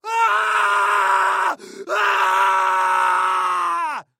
Звуки мужские
Звук кричащего человека на грани срыва